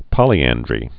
(pŏlē-ăndrē)